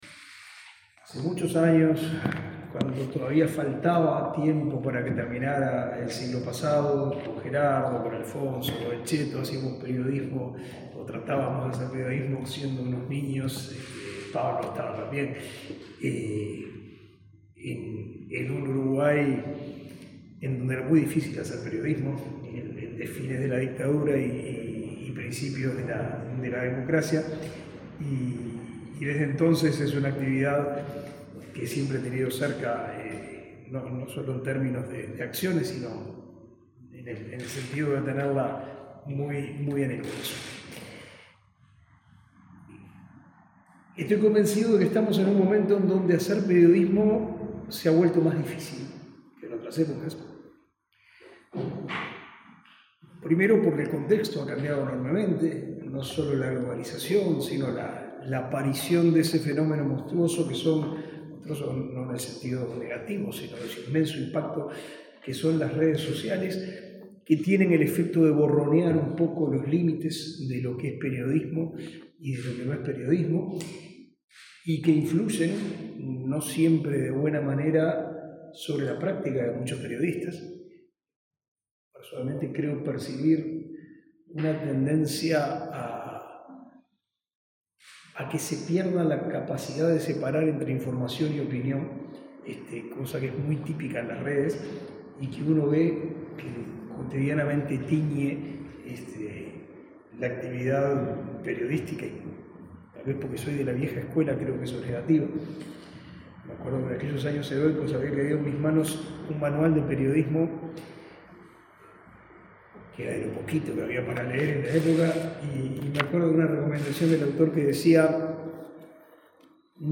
Palabras del ministro de Educación y Cultura, Pablo da Silveira
El Servicio de Comunicación Audiovisual Nacional (Secan) presentó, este miércoles15 en la Biblioteca Nacional, la guía de Principios, Procedimientos